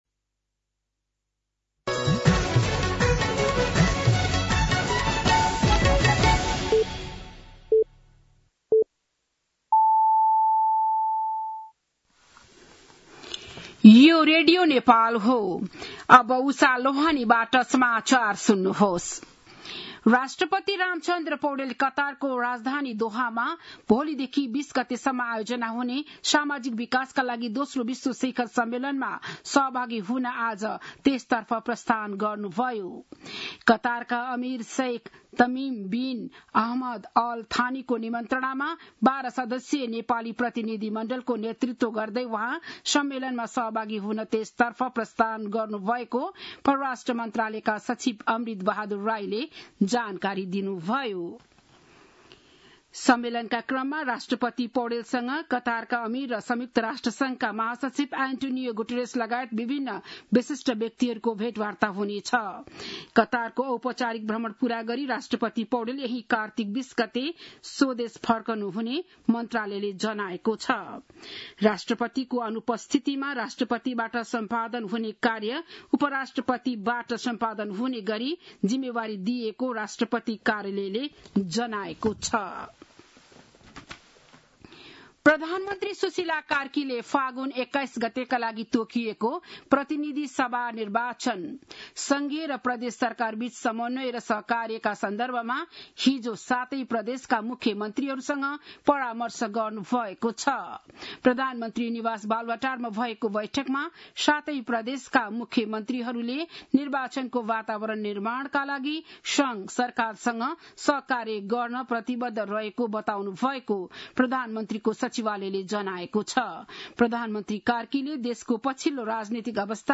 बिहान ११ बजेको नेपाली समाचार : १७ कार्तिक , २०८२
11-am-News-7-17.mp3